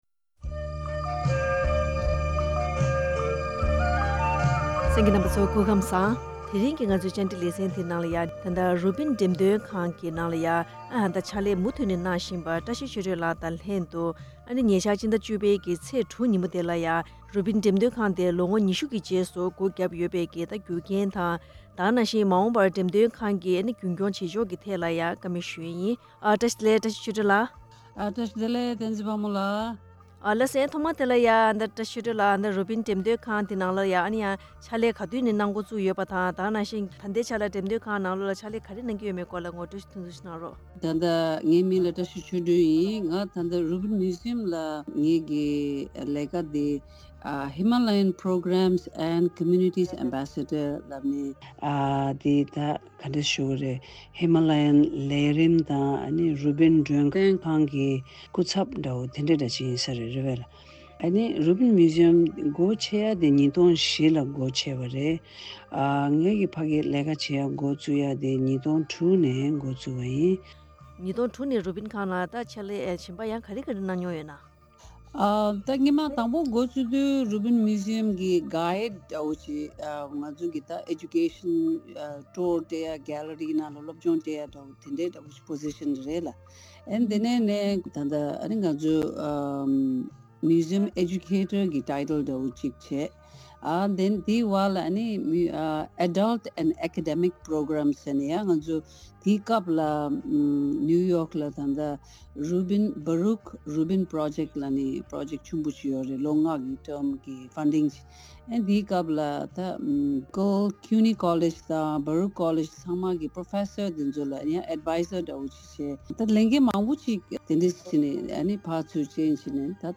བཀའ་འདྲི་ཞུས་པ་ཞིག་གསན་གནང་གི་རེད།